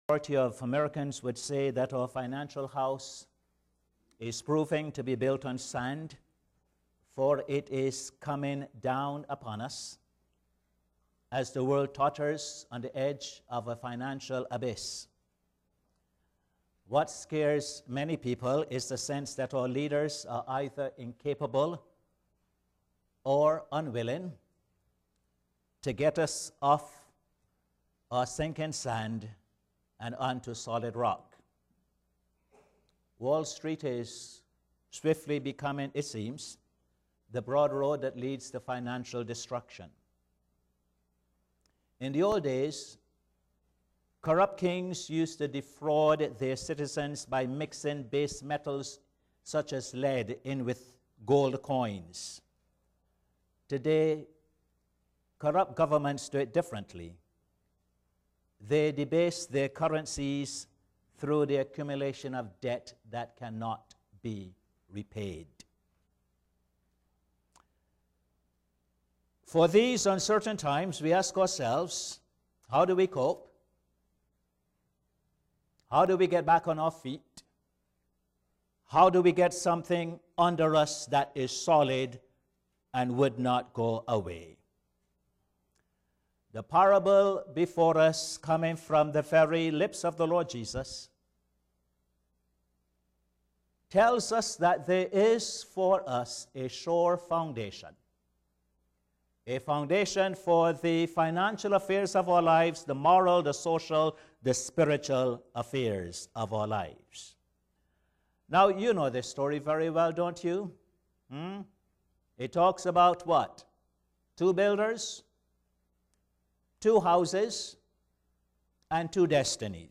Posted in Sermons on 15. Aug, 2011